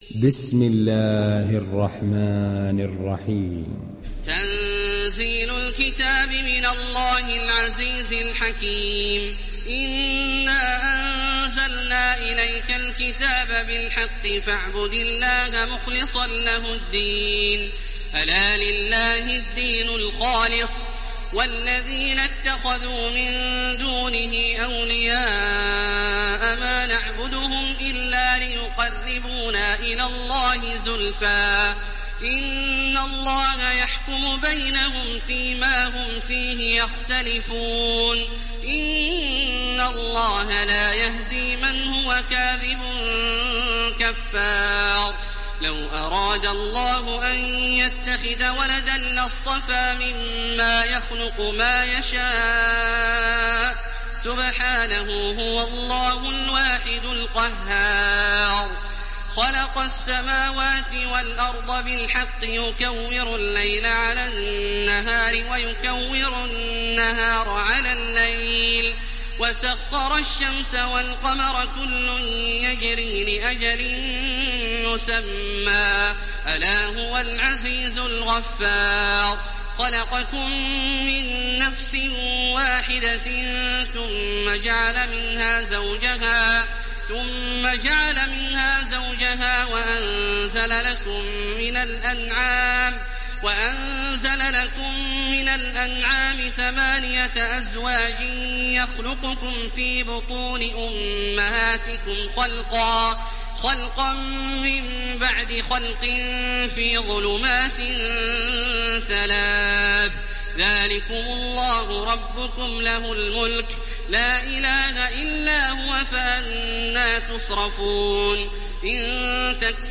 Download Surat Az zumar Taraweeh Makkah 1430